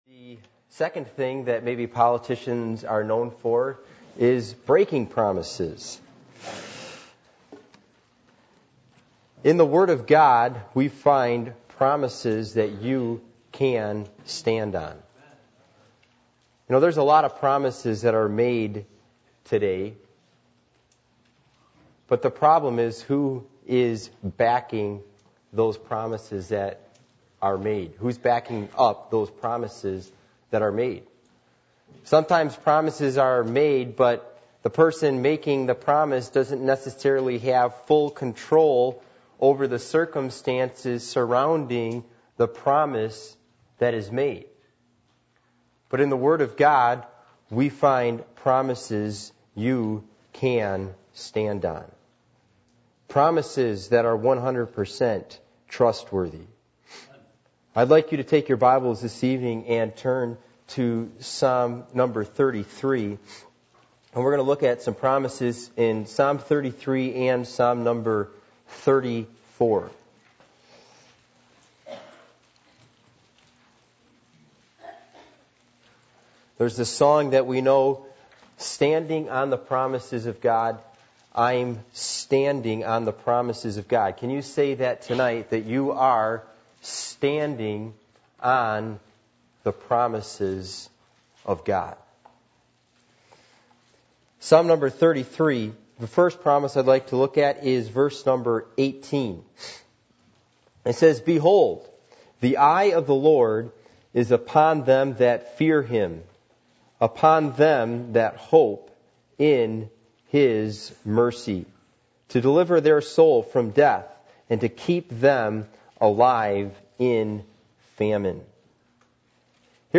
Passage: Psalm 34:1-22, Psalm 33:1-22 Service Type: Midweek Meeting